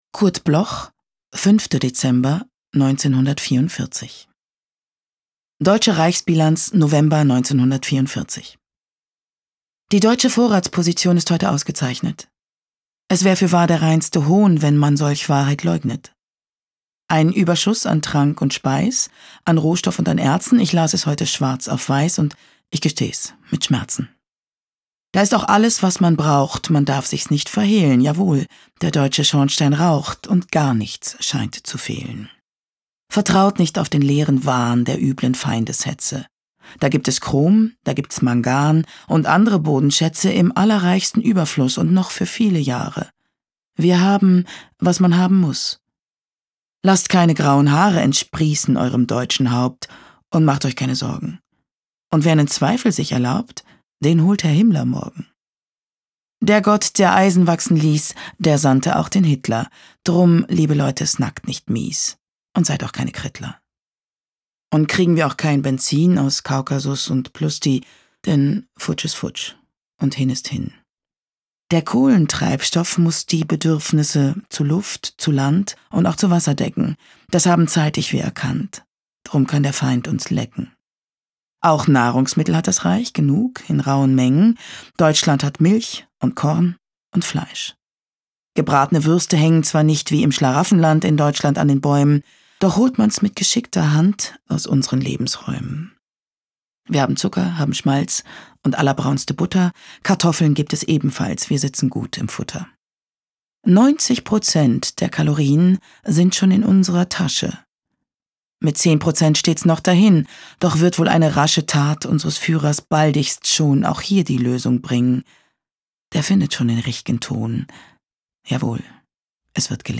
Anja Herden (* 1970) ist eine deutsche Schauspielerin.
Aufnahme: speak low, Berlin · Bearbeitung: Kristen & Schmidt, Wiesbaden